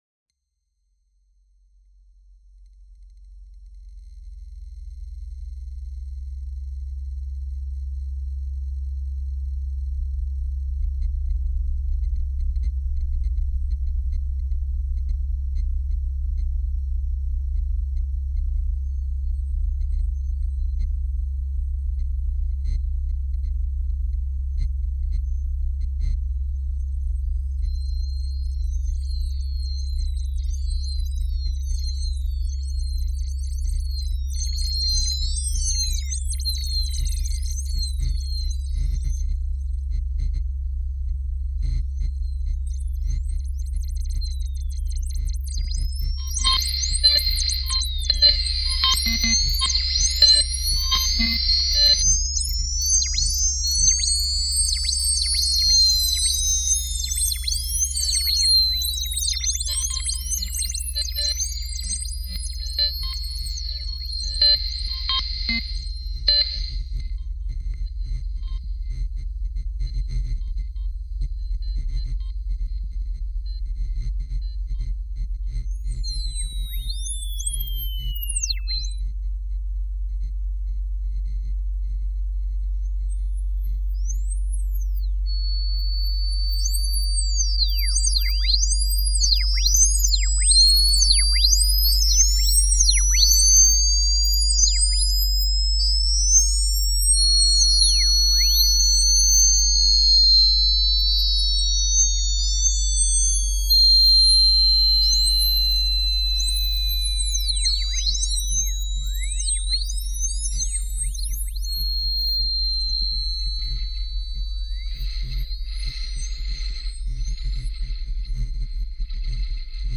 two works inspired by deep sea diving filtered through 8bit game audio.